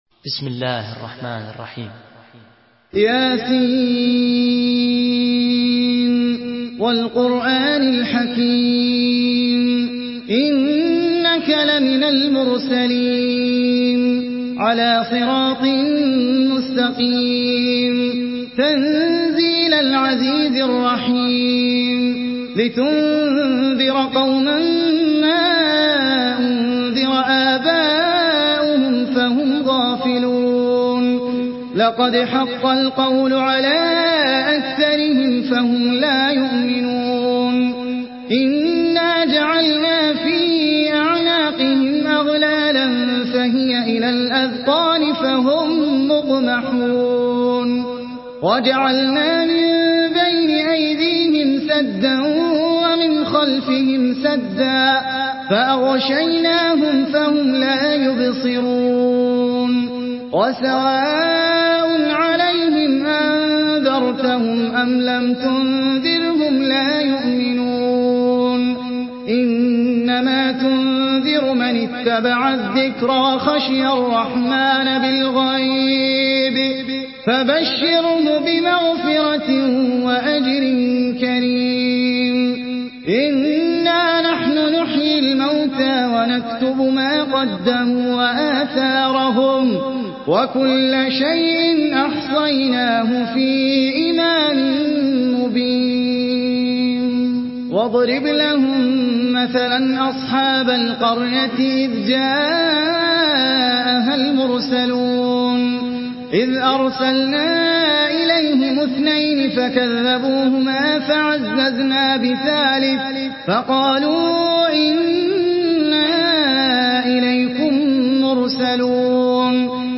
Surah Yasin MP3 in the Voice of Ahmed Al Ajmi in Hafs Narration
Murattal Hafs An Asim